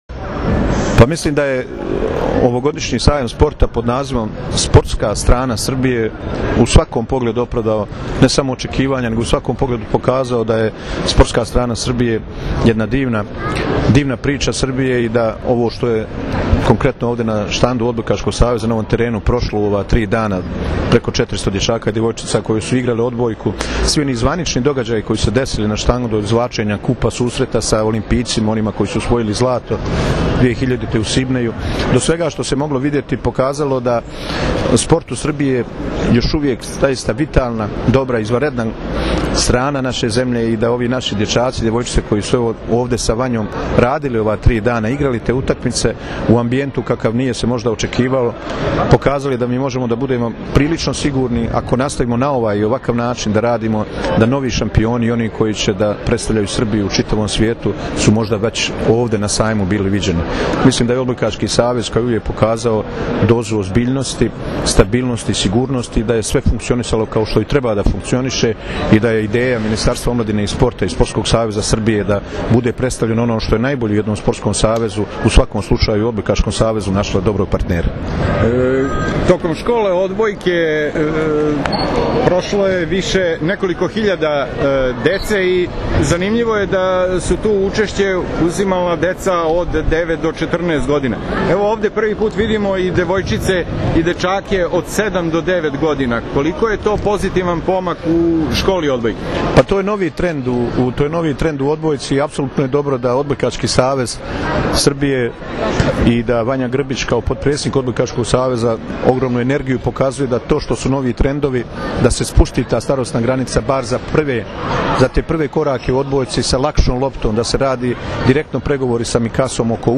III MEĐUNARODNI SAJAM SPORTA – “SPORTSKA STRANA SRBIJE”
IZJAVA